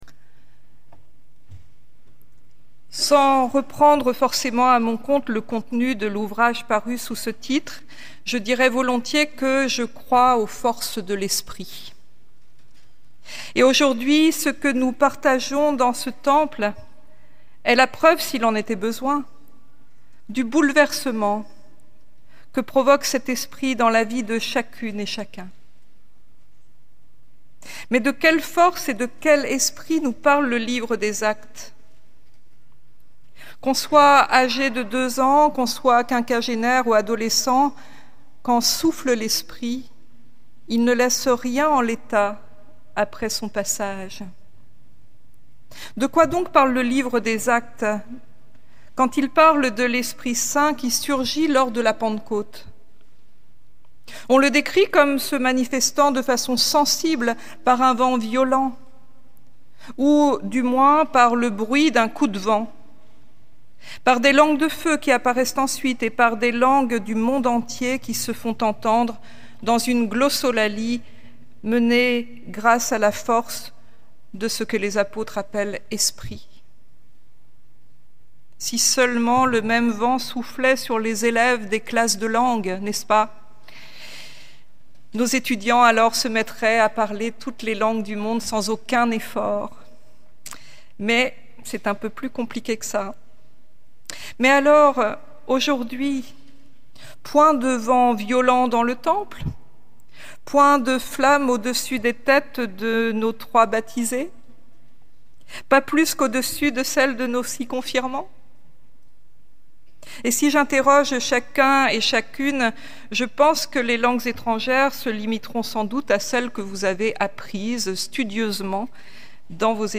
Culte à l’Oratoire du Louvre